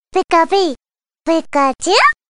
Звуки Пикачу
Голос Пикачу